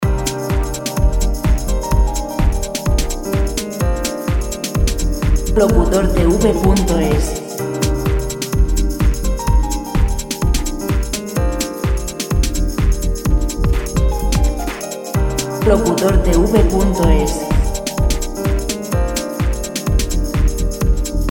Royalty free dance music